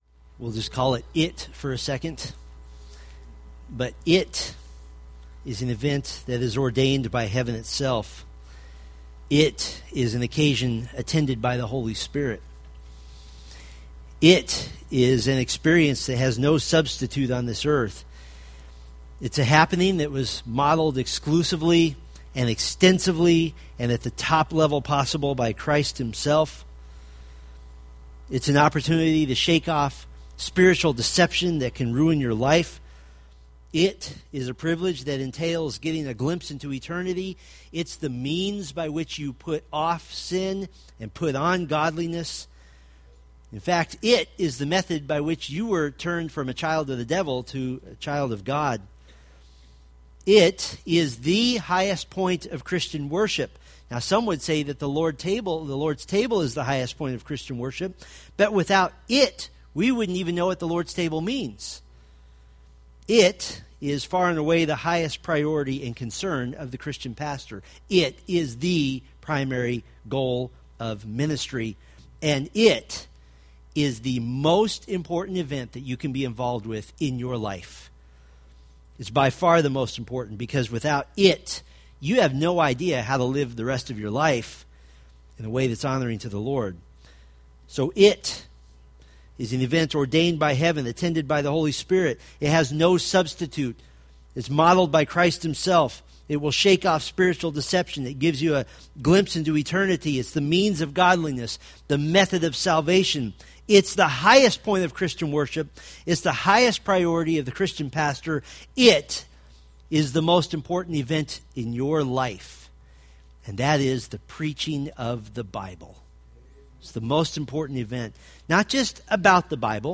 2 Corinthians Sermon Series